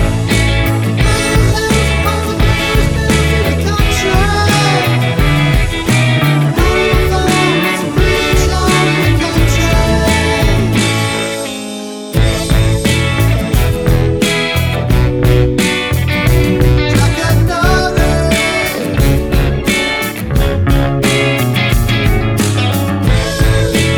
no Backing Vocals Indie / Alternative 3:52 Buy £1.50